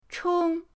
chong1.mp3